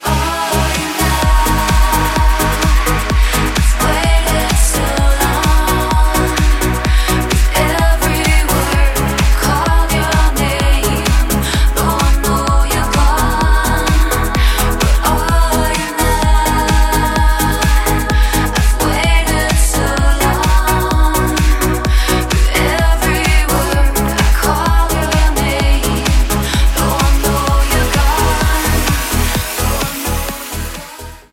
• Качество: 128, Stereo
танцевальная